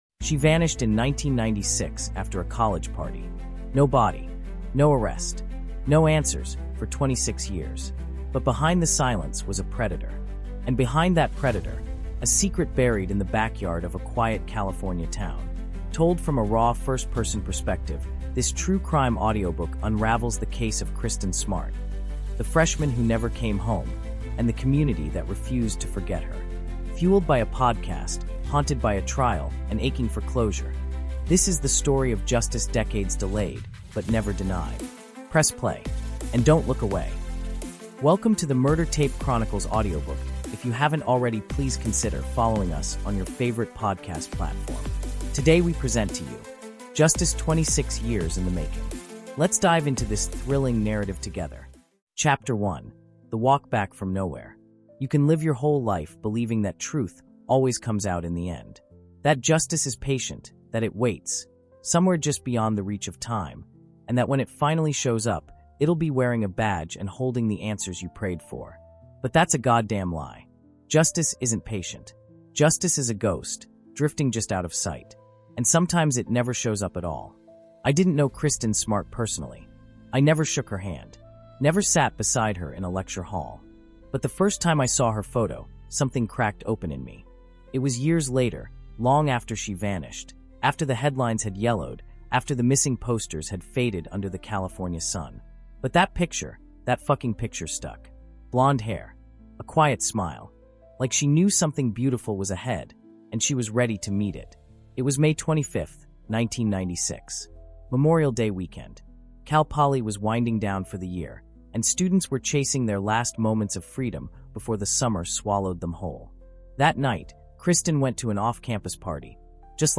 Justice 26 Years In The Making | Audiobook
Told in a gripping first-person narrative, this immersive audio experience brings listeners face to face with a chilling tale of silence, cover-ups, and a justice system that waited over two decades to act.